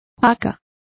Also find out how jaca is pronounced correctly.